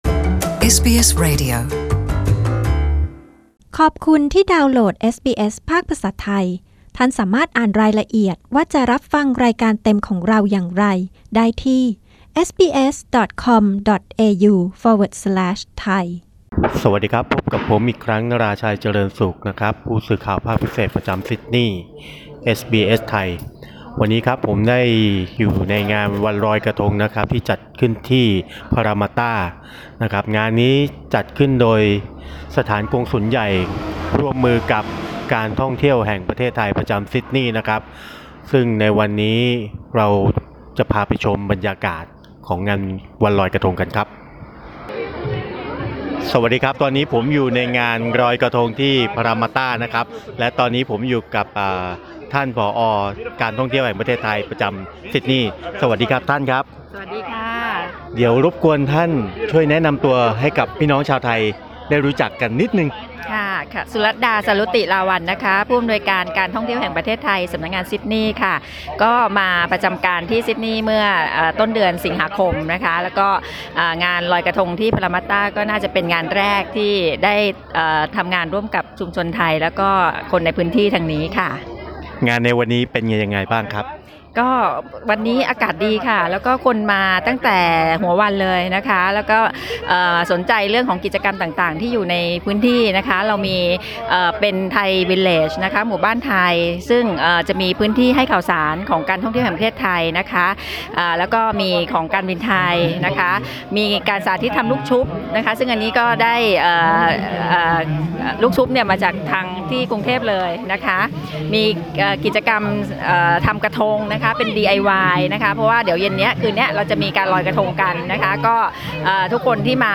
เอสบีเอส ไทย มีบรรยากาศความสนุกสนานจากงานลอยกระทงพารามัตตา 2018 มาฝากคุณผู้ฟัง ซึ่งงานนี้ร่วมกันจัดขึ้นโดยการท่องเที่ยวแห่งประเทศไทย สถานกงสุลใหญ่ไทย ณ นครซิดนีย์ และเทศบาลเมืองพารามัตตา โดยนอกจากจะเป็นการสืนสานและแสดงออกประเพณีอันสืบทอดมายาวนานของไทยแล้ว ยังรื่นเริงครื้นเครงและยิ่งใหญ่ไม่แพ้ทุกปีที่ผ่านมา